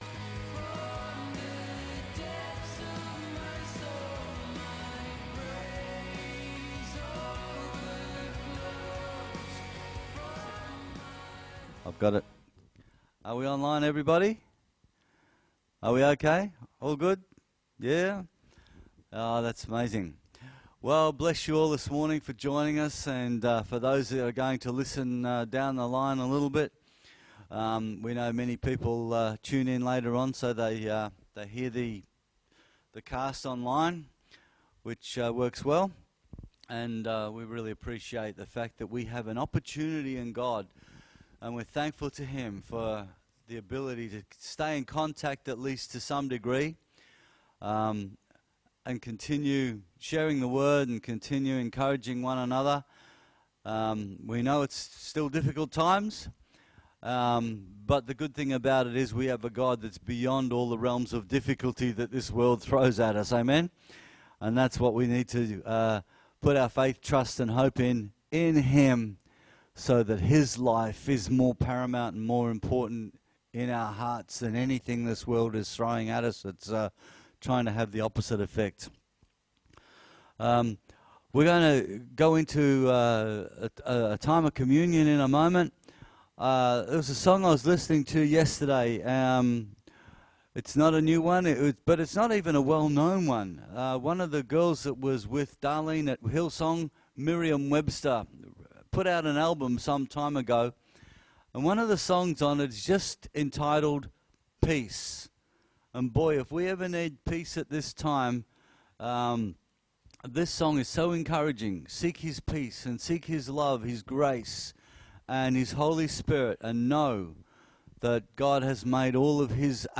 Sunday Service – 20/09/20 (Audio only)